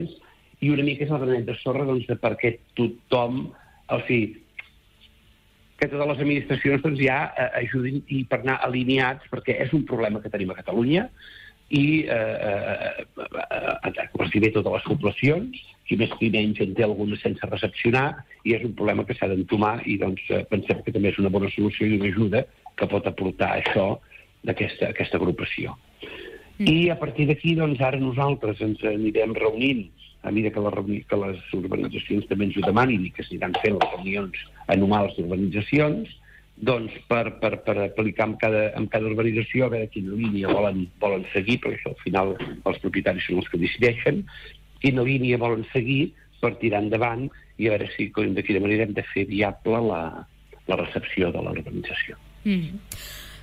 Un altre dels alcaldes que van assistir a la jornada d’aquest dimecres va ser l’alcalde de Santa Cristina d’Aro, Josep Xifre. En una entrevista al Supermatí, el batlle va admetre que al municipi hi ha actualment nou urbanitzacions, set de les quals no estan recepcionades. Per això, apunta que calia presentar aquest manifest per exigir millores a la Generalitat i al Parlament quan es parla de recepcionar aquestes urbanitzacions amb mancances.